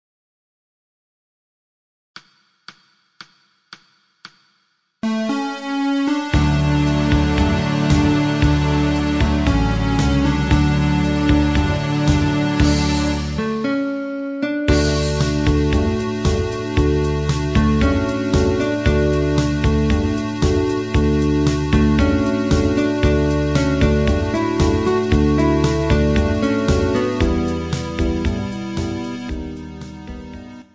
• Backing Track: Midi File